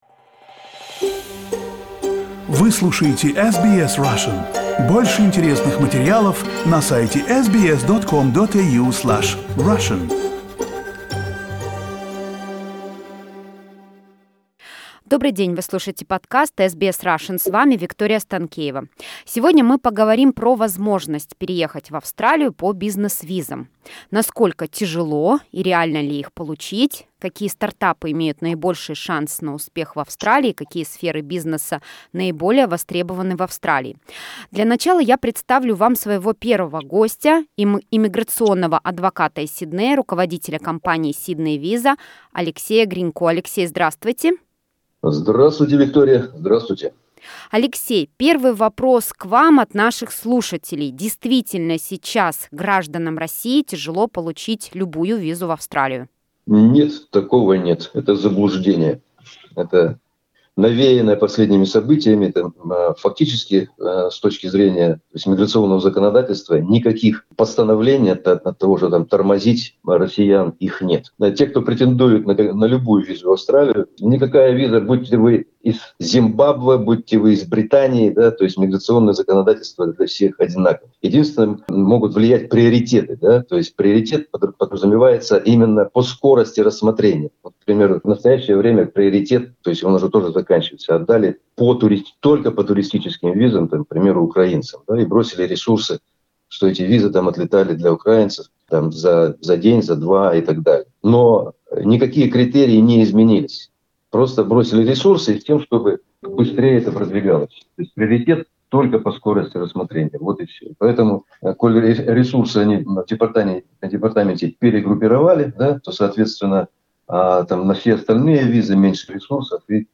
Эта беседа предназначена только для общих информационных целей и не должна быть использована как замена консультаций со специалистами.